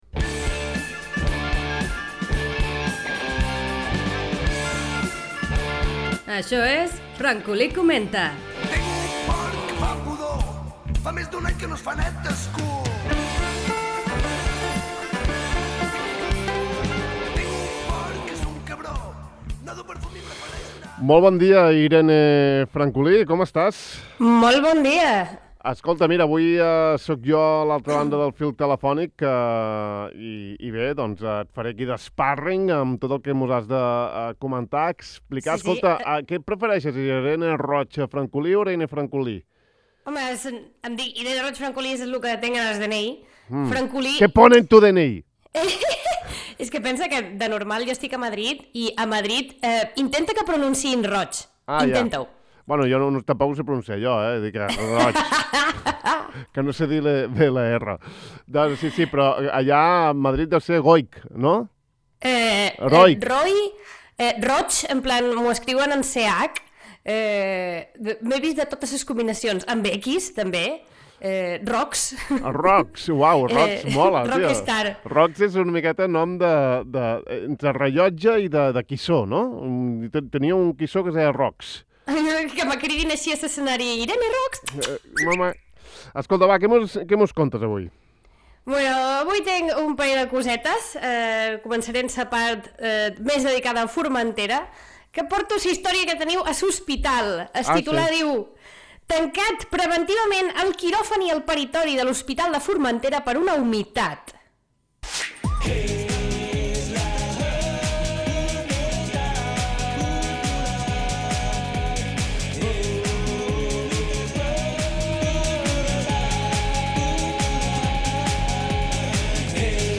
Avui, les humitats de l'hospital de Formentera i les rotondes a l'espai de sàtira